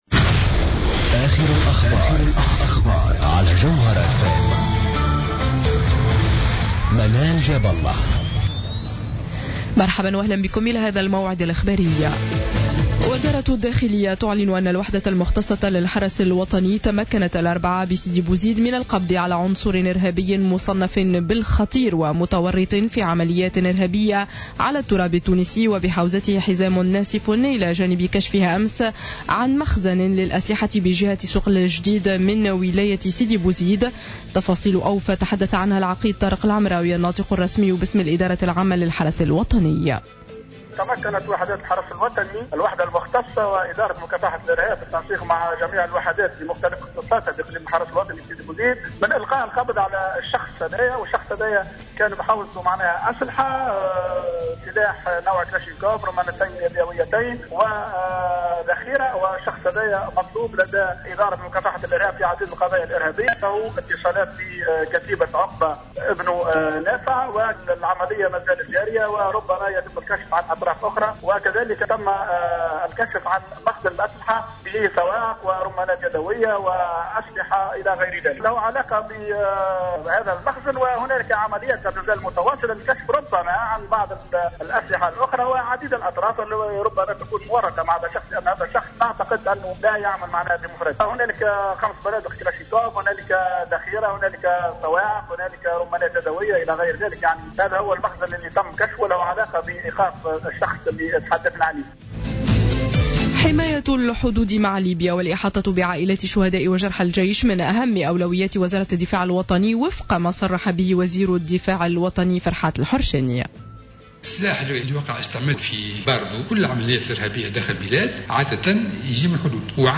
نشرة أخبار منتصف الليل ليوم الجمعة 17 أفريل 2015